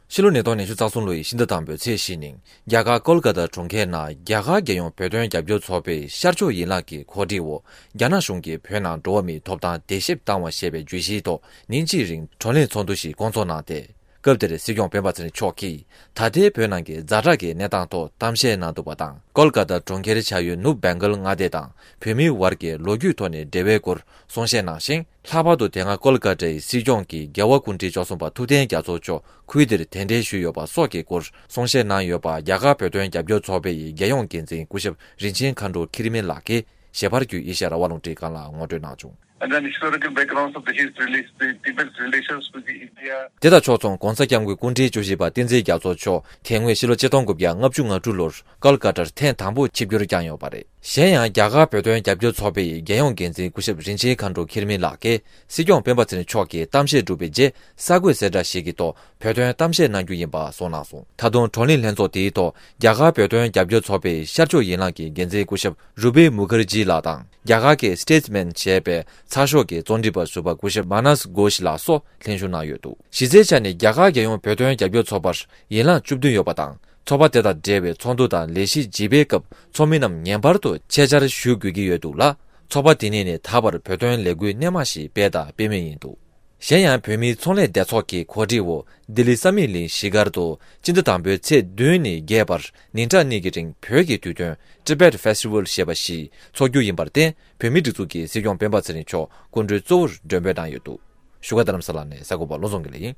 ཀ་ལི་ཀ་ཏཱར་བོད་དོན་བགྲོ་གླེང་སྐབས་སྲིད་སྐྱོང་མཆོག་ཕེབས་ནས་གཏམ་བཤད་གནང་འདུག
རྒྱ་གར་གྱི་གྲོང་ཁྱེར་ཀ་ལི་ཀ་ཏཱར་རྒྱ་ནག་གཞུང་གིས་བོད་ནང་གི་འགྲོ་བ་མིའི་ཐོབ་ཐང་བརྡལ་བཤིག་བཏང་བ་ཞེས་པའི་བརྗོད་གཞིའི་ཐོག་བགྲོ་གླེང་གནང་བར་སྲིད་སྐྱོང་སྤེན་པ་ཚེ་རིང་ལགས་ཕེབས་ནས་གཏམ་བཤད་གནང་འདུག